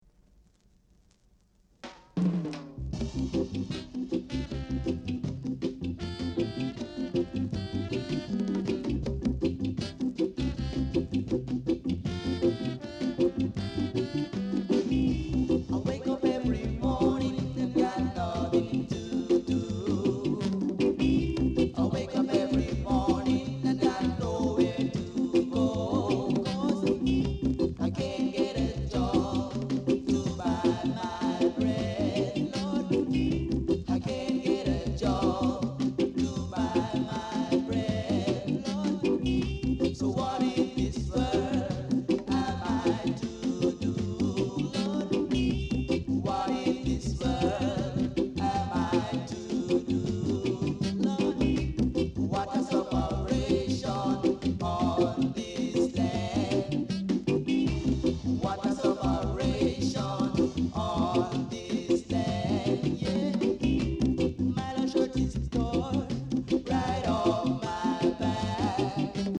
Reggae Inst, Male Vocal